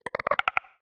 minecraft / sounds / mob / strider / idle3.ogg